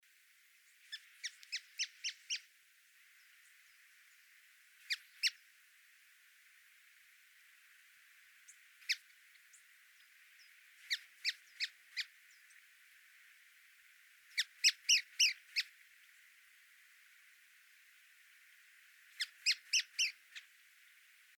Piojito Gargantilla (Mecocerculus leucophrys)
Nombre en inglés: White-throated Tyrannulet
Localidad o área protegida: Ruta Provincial 4
Condición: Silvestre
Certeza: Observada, Vocalización Grabada
PiojitoGargantilla.mp3